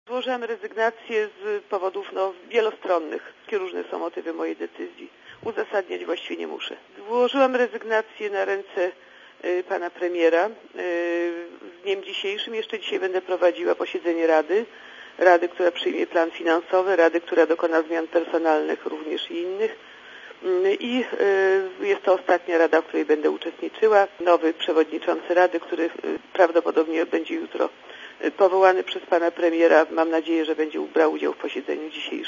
Elżbieta Chojna-Duch nie chce zdradzić powodów swojej decyzji, ale jak powiedziała Radiu Zet, dziś ostatni raz weźmie udział w posiedzeniu rady Funduszu.